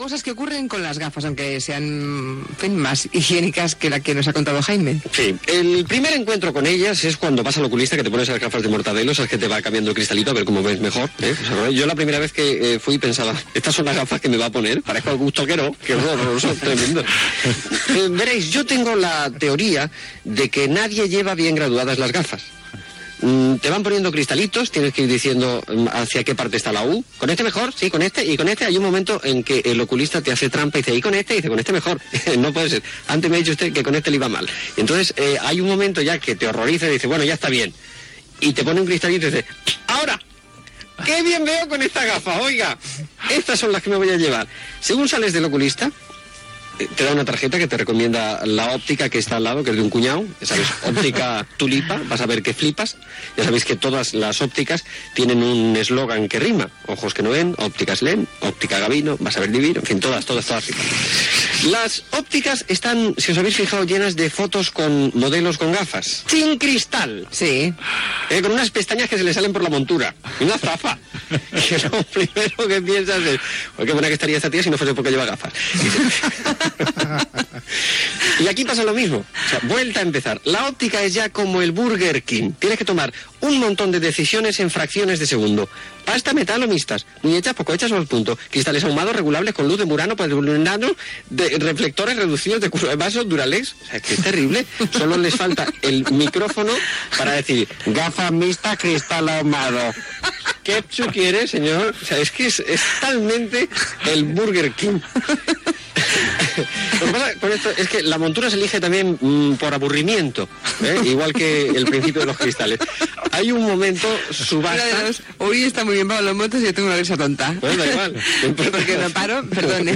Intervenció del col·laborador Pablo Motos sobre les òptiques i les muntures de les ulleres i la seva graduació
Entreteniment